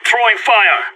CSGO Throwing Fire Sound Effect Free Download
CSGO Throwing Fire